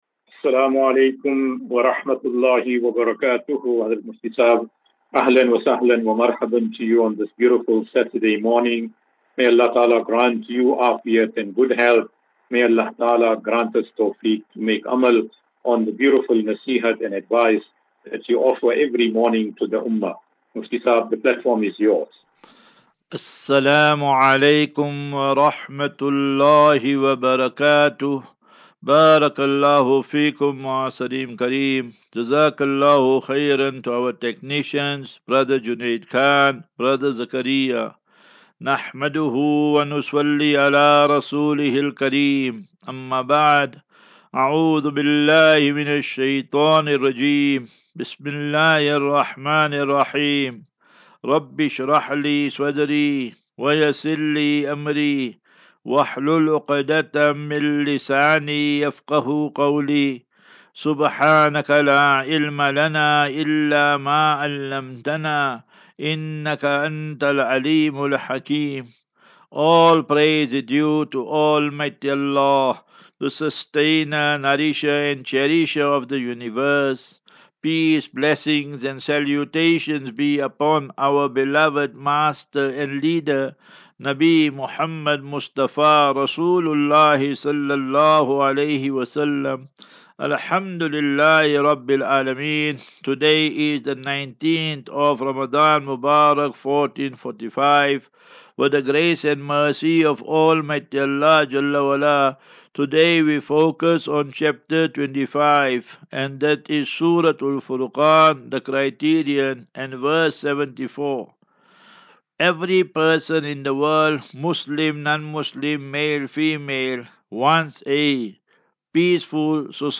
As Safinatu Ilal Jannah Naseeha and Q and A 30 Mar 30 March 2024.